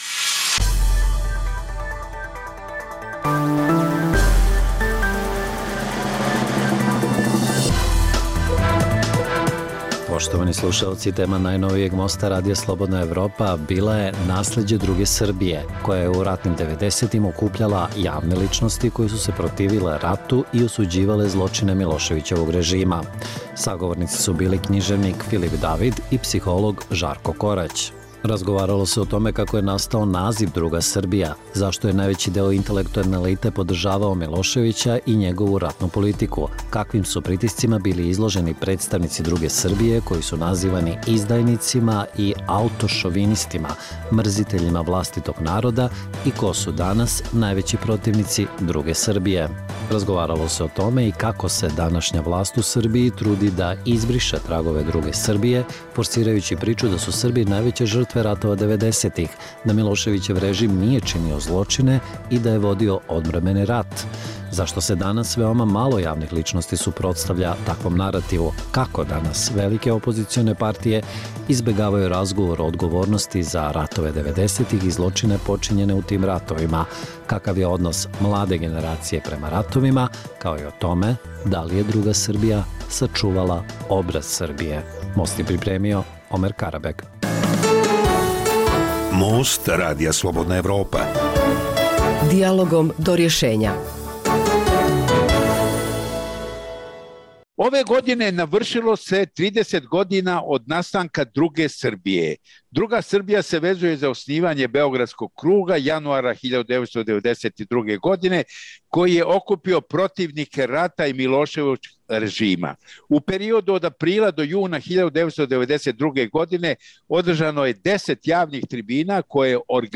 U najnovijem Mostu vođen je razgovor o tome da li su ideje Druge Srbije prisutne u današnjem srpskom društvu. Sagovornici su književnik Filip David, jedan od osnivača Beogradskog kruga, i psiholog Žarko Korać, član Kruga od njegovog osnivanja.